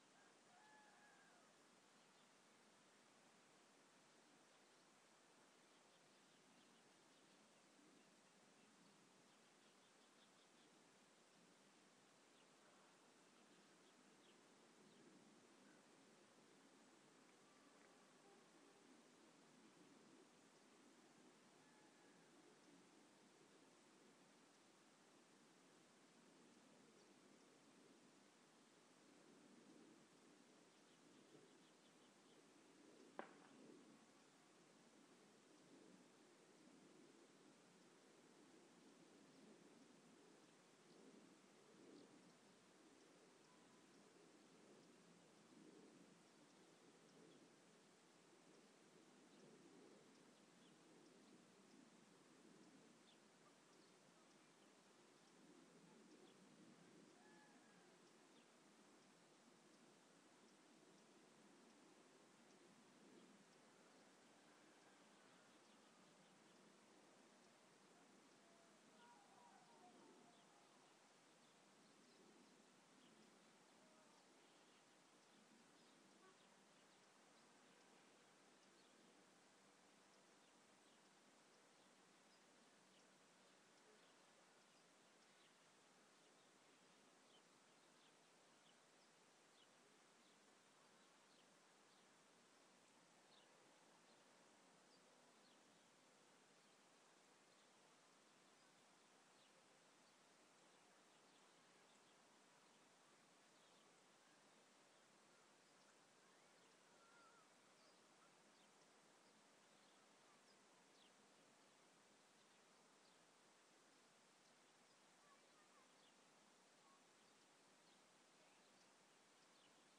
The sound of 2 minutes of silence in Barga Tuscany
This is two minute of silence recorded up by the Duomo in Barga Vecchia with the microphone pointing towards the mountains behind Barga